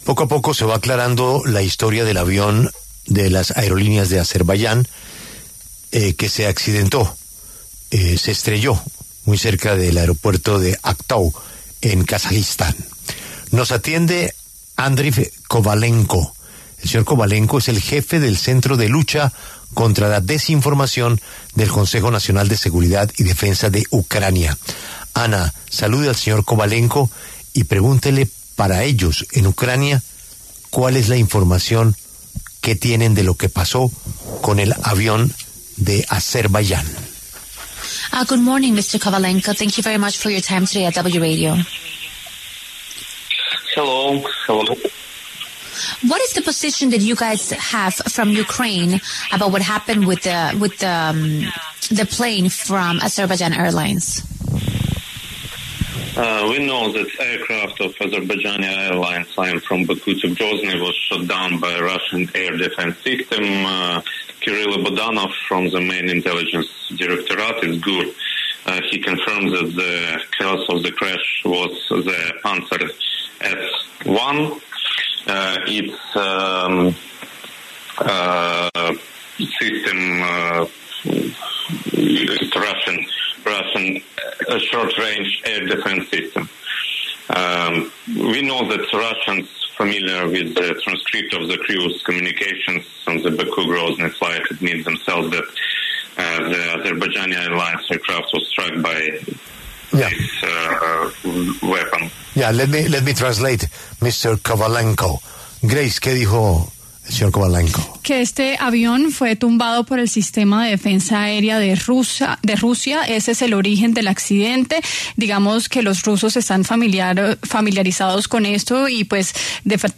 En entrevista con La W, Kovalenko aseguró que “este avión fue tumbado por el sistema de defensa aéreo de Rusia, ese es el origen del accidente. Los rusos están familiarizados con esto”.